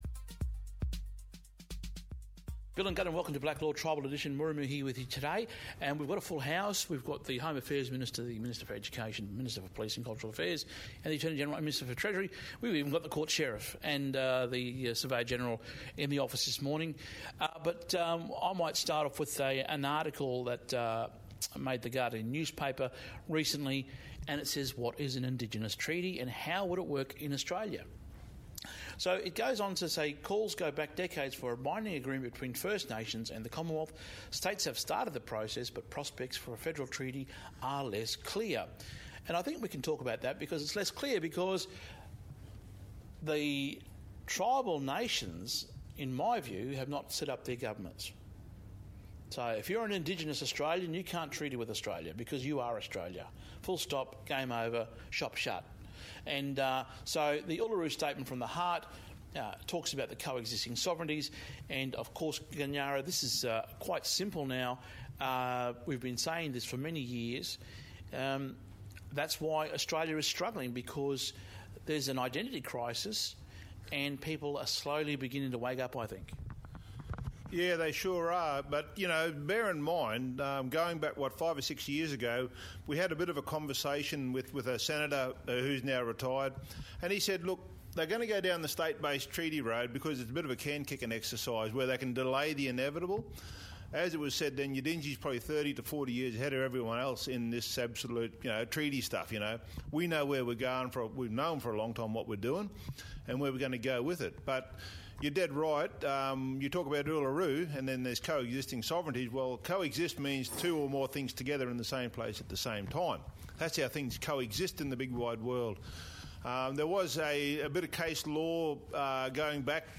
We going big on sovereignty with an action packed panel